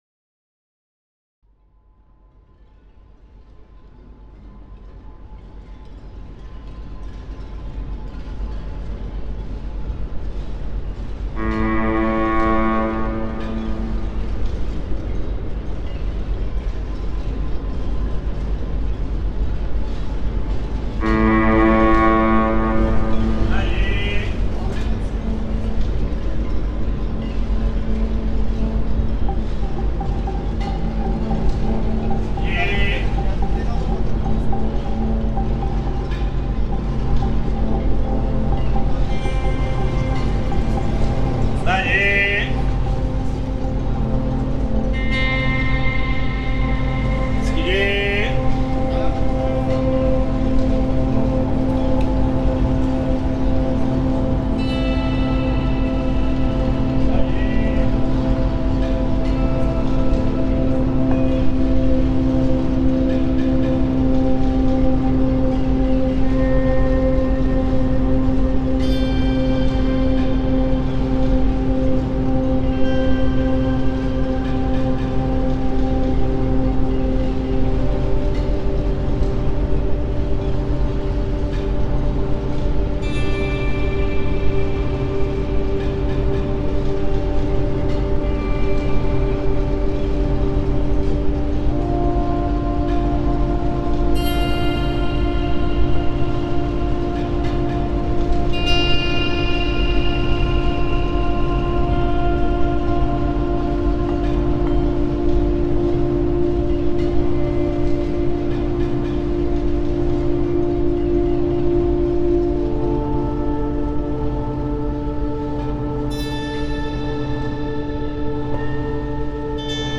Call to prayer in Istanbul reimagined